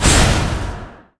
poison_cloud_explo.wav